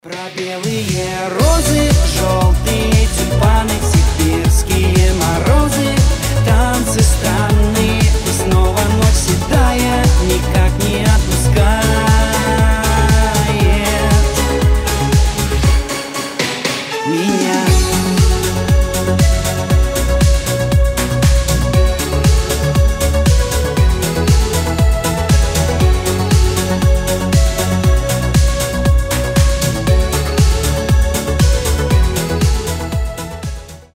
• Качество: 320, Stereo
диско
Cover